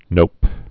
(nōp)